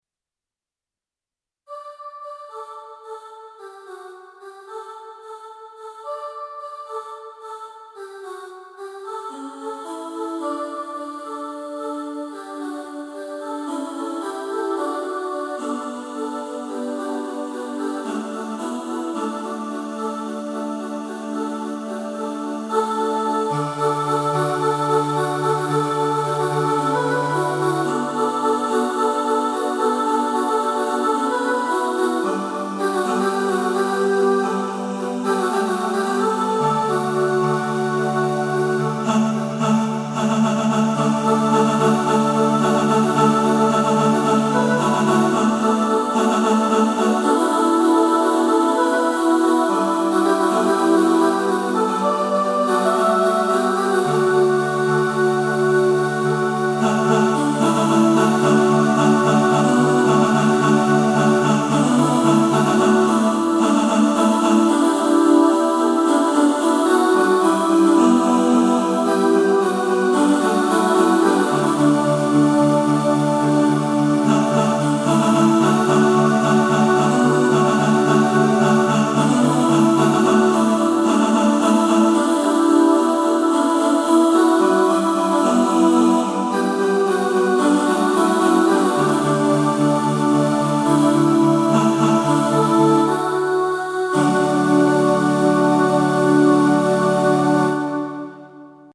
Just-The-Way-You-Are-All-Voices | Ipswich Hospital Community Choir